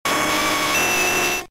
Cri de Nidoking K.O. dans Pokémon Diamant et Perle.